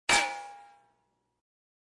ting.1.ogg